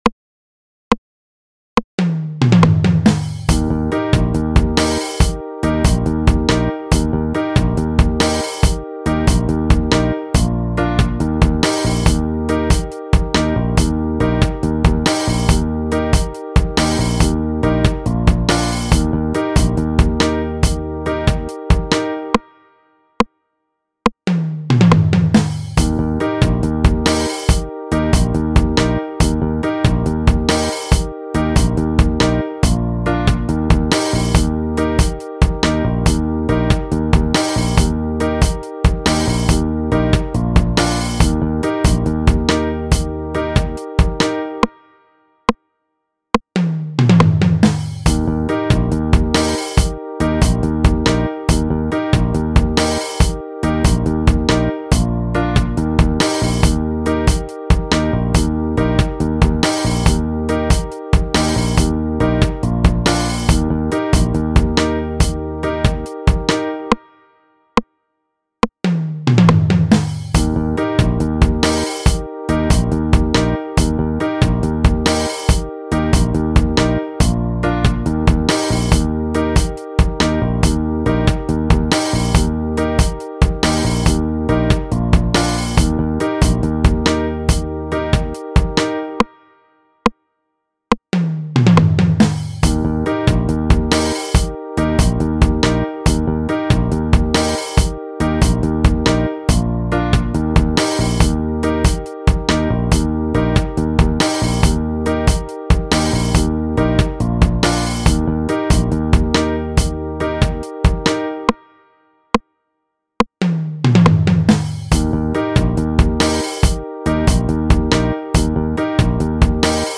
Concert_Eb_Alto_C_Major_Scale.mp3